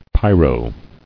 [py·ro]